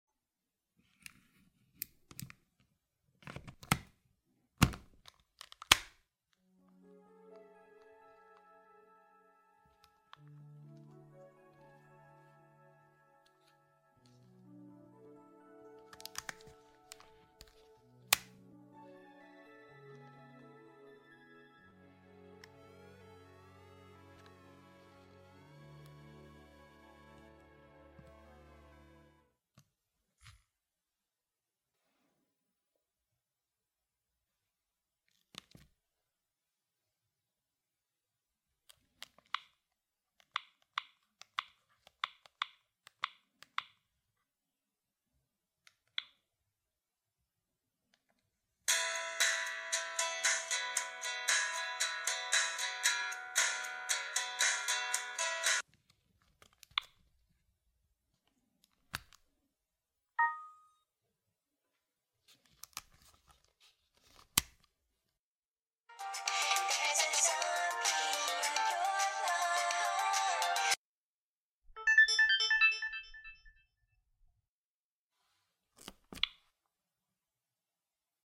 Asmr Sony Ericsson W550 quick sound effects free download
Asmr Sony Ericsson W550 quick demo.